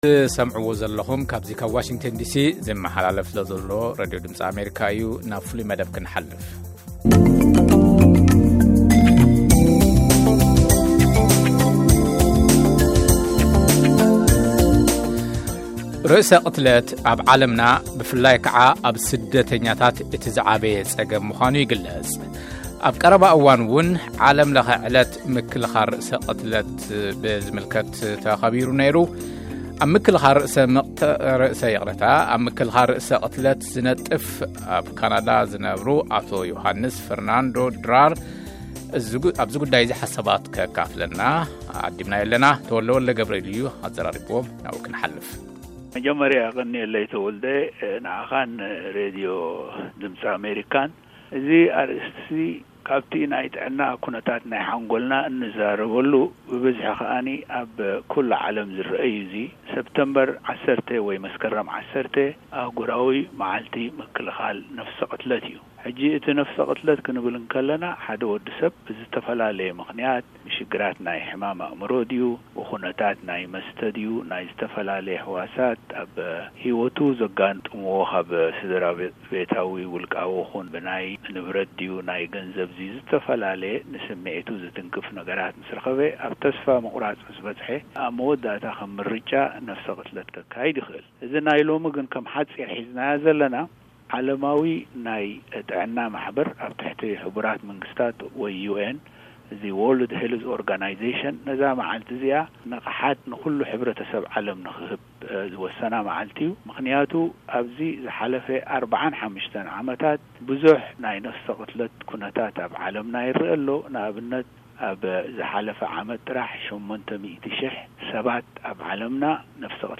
ቃለ-መጠይቅ